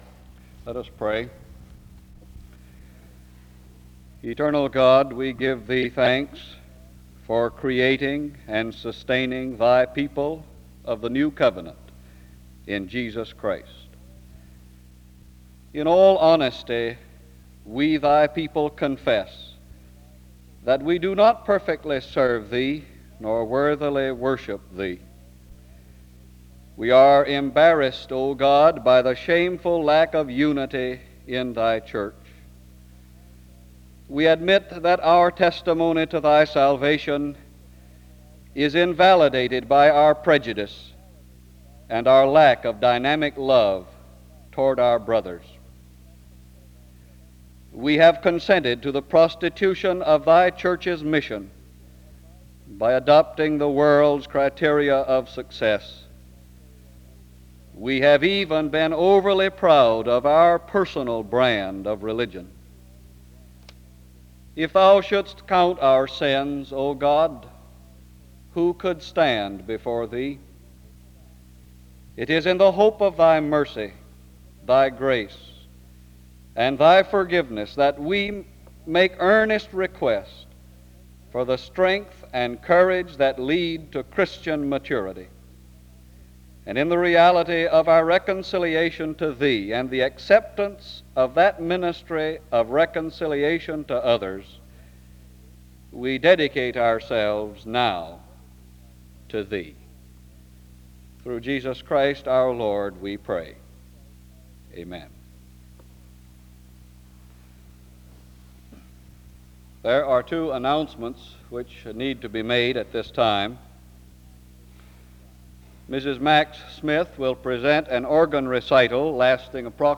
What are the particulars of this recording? The service opens with a word of prayer from 0:00-1:45. A couple of announcements are given from 1:49-2:38. The service closes in prayer from 40:35-40:58. This lecture was part 3 of a 4 part series.